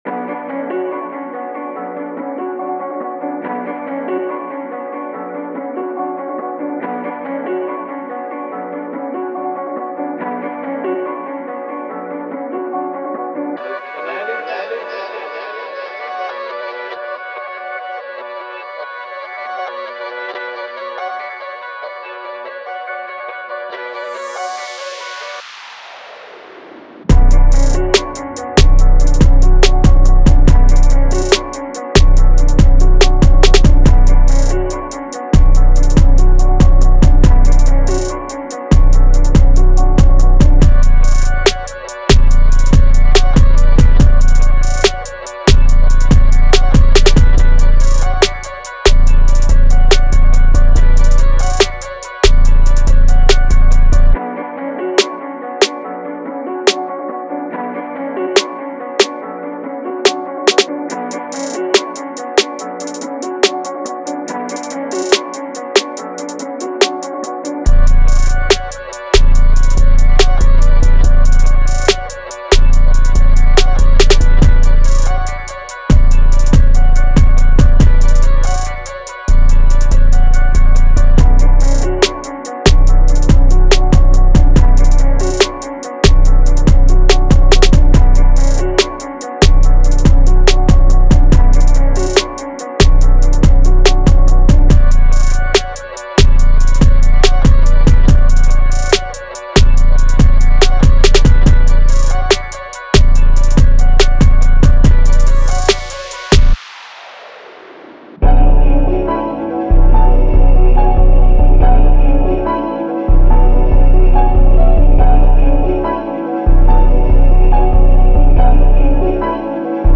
Hip Hop
B Minor